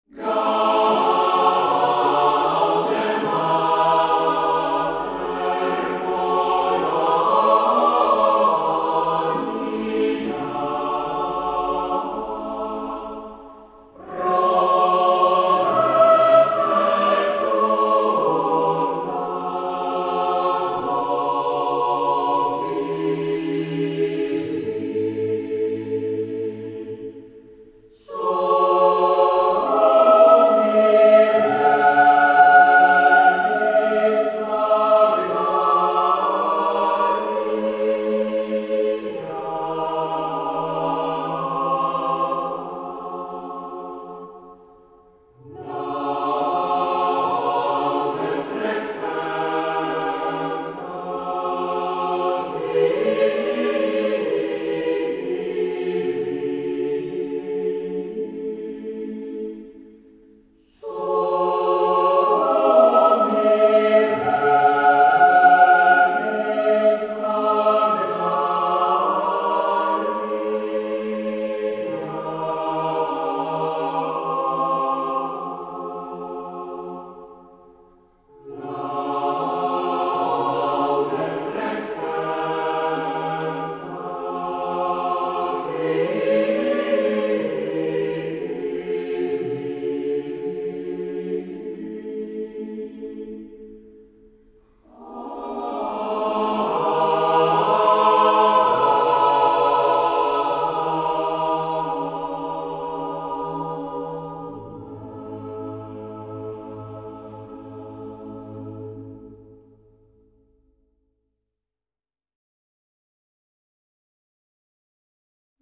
Kromě toho by ale byl hrozně rád, abychom na závěr společně zapěli hymnus Gaude Mater Polonia, což je upravený středověký hymnus na počest sv.